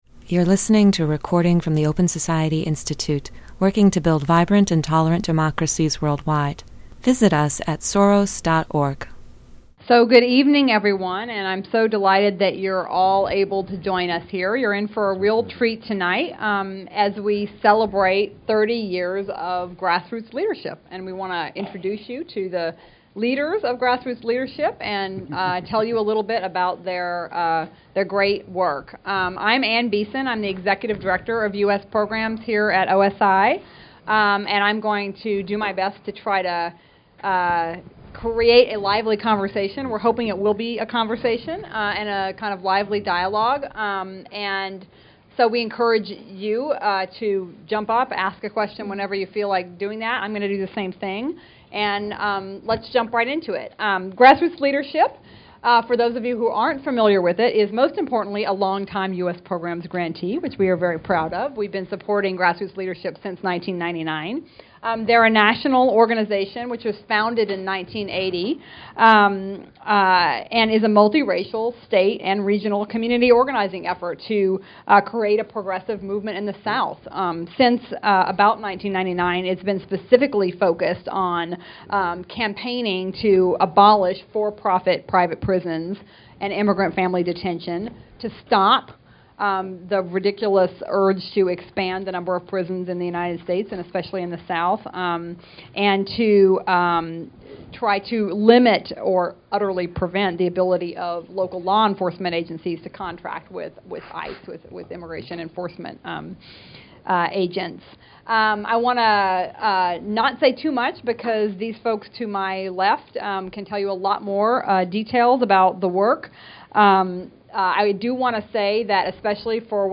Speakers at this event reflect upon decades of work in a multiracial community organizing effort focusing on ending private prisons and immigrant family detention.